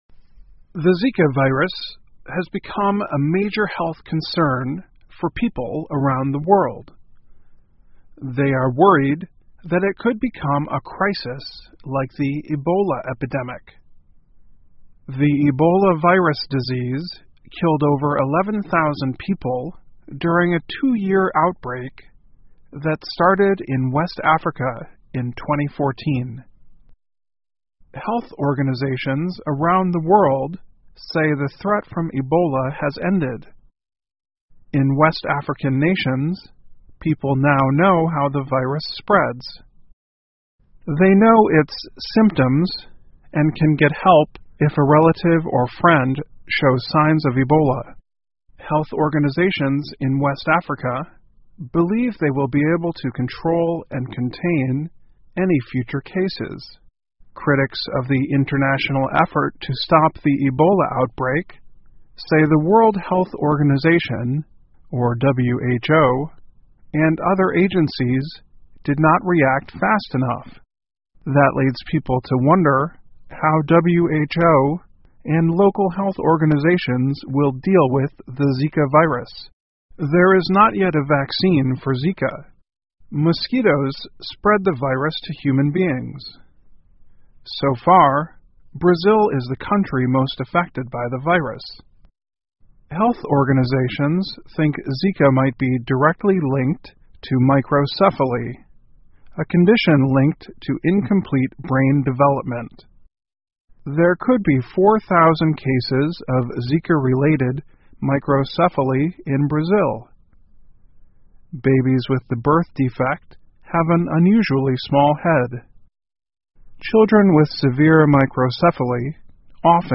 VOA慢速英语2016 埃博拉危机对寨卡病毒应对的启发 听力文件下载—在线英语听力室